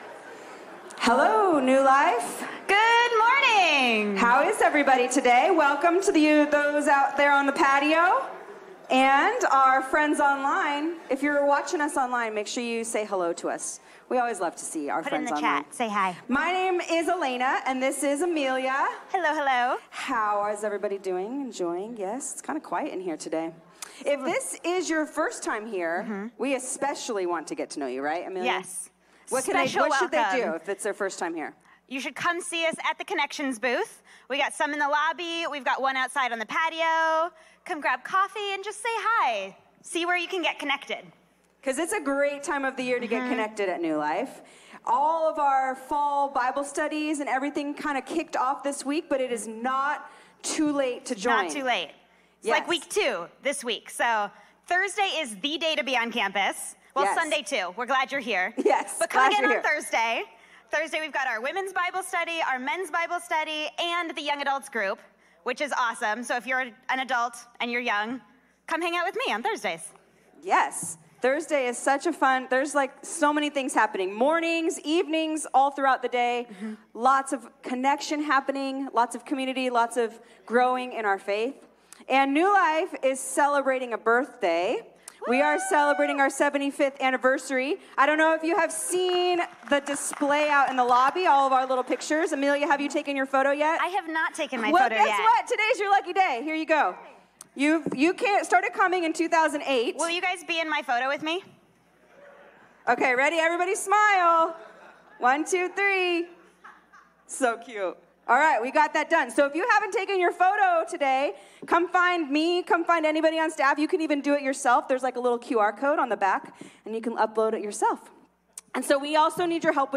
A message from the series "No Longer Strangers."